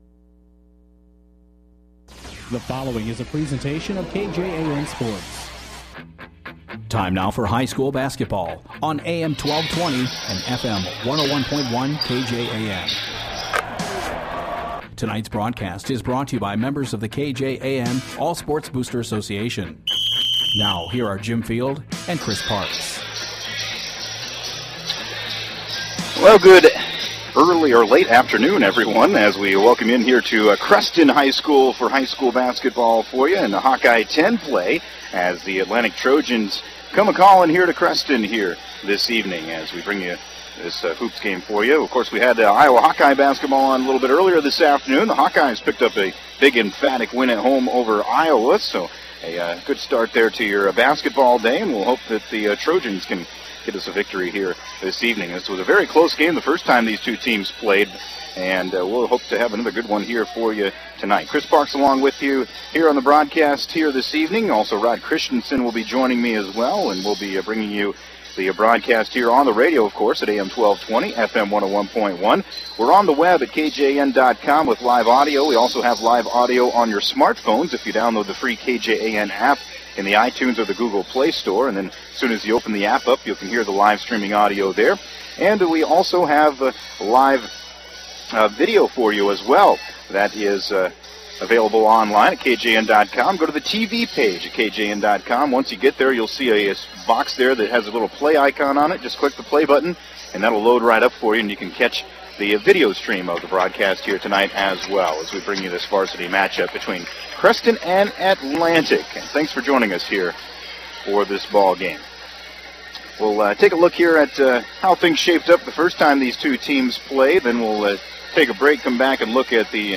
have the call of the game played at Creston High School.